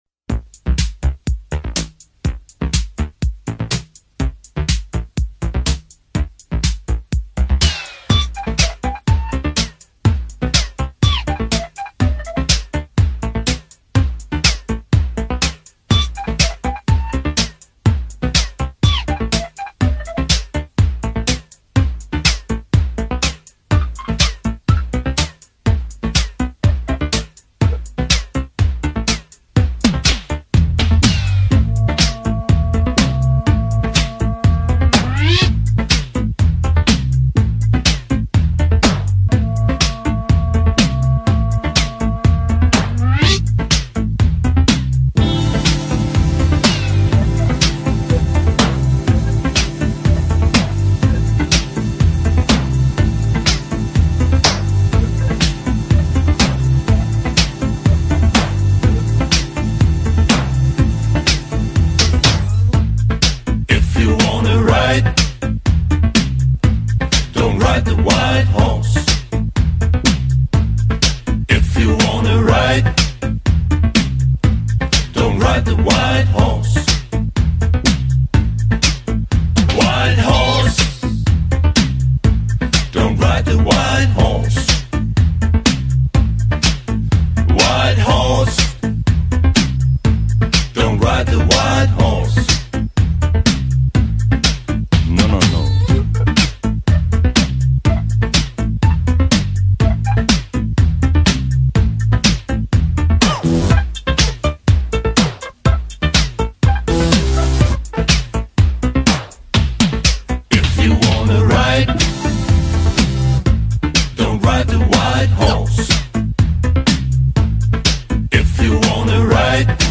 Tag Archives: New Wave